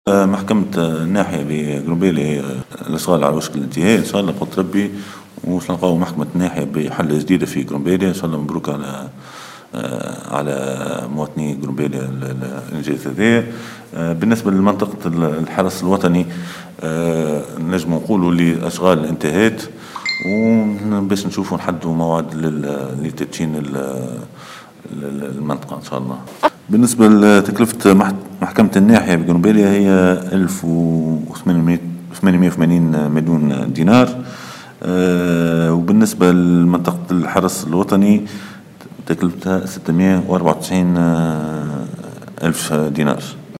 أكثر تفاصيل في تصريح طارق صالح معتمد قرمبالية